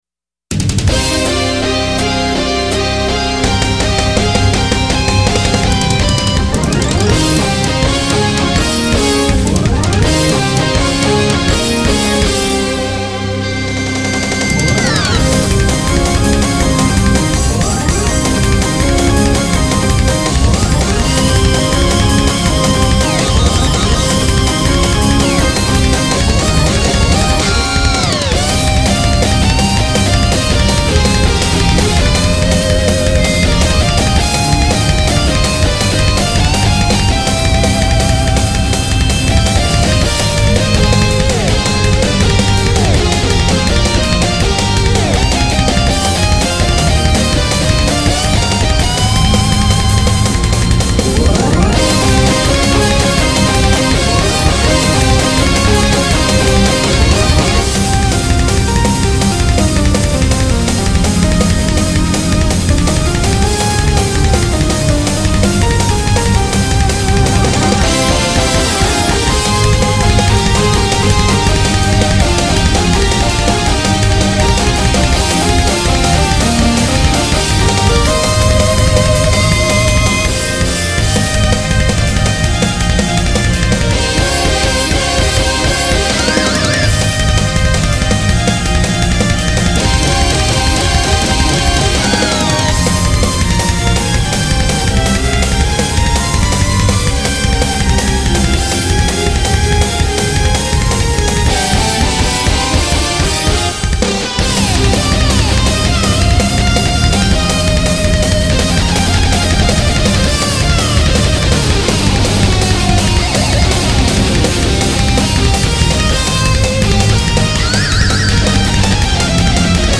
YAMAHA MU2000を使ってゲームBGMのメタルアレンジをしています